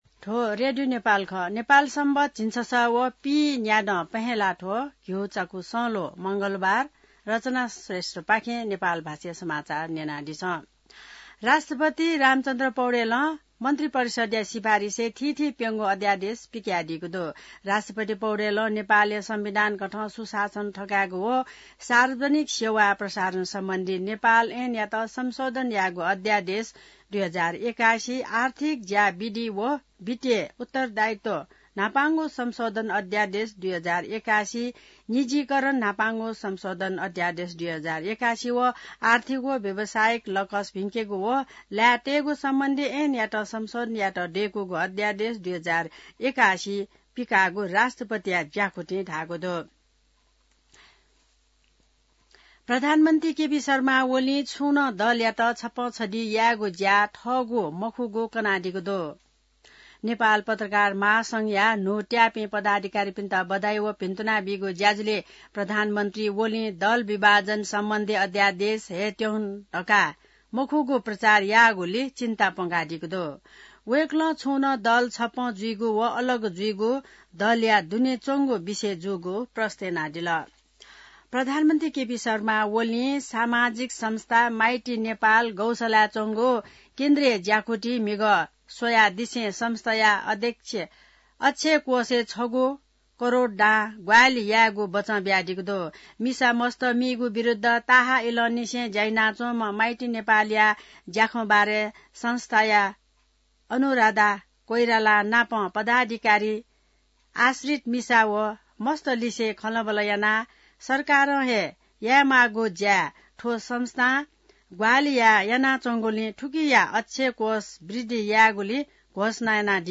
नेपाल भाषामा समाचार : २ माघ , २०८१